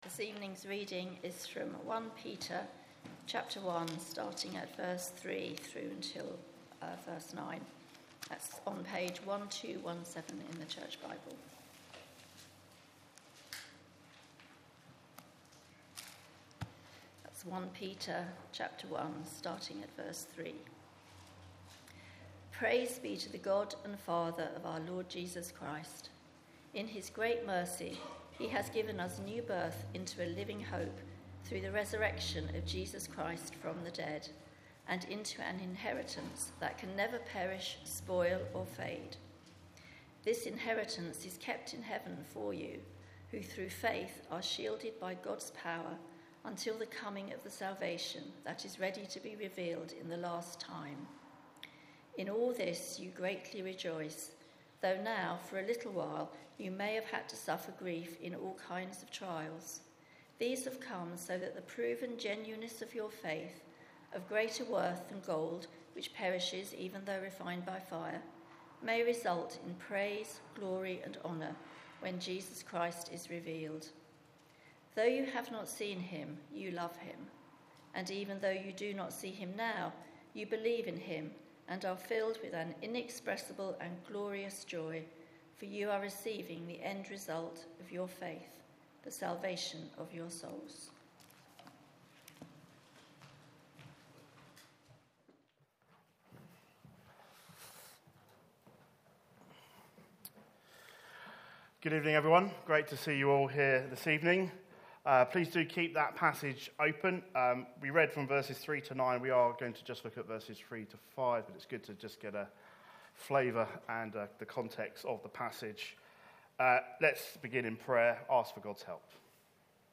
Sermons | ChristChurch Banstead